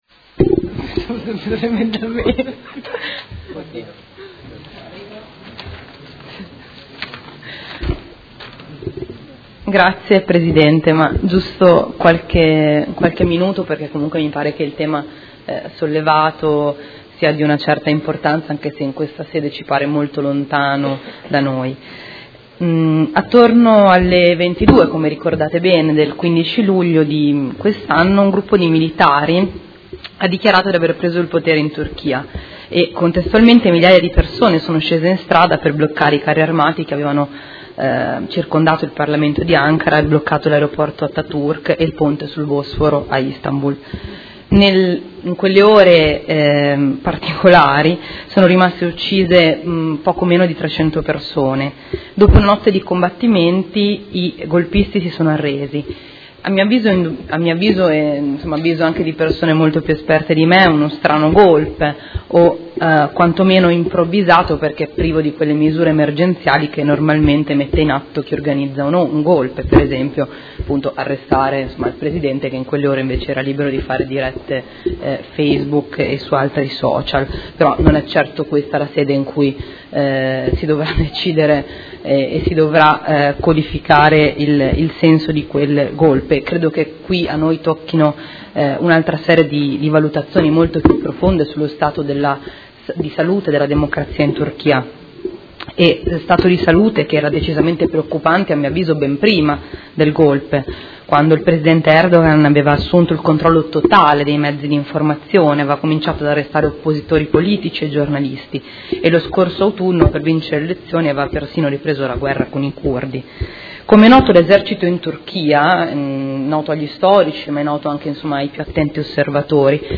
Federica Di Padova — Sito Audio Consiglio Comunale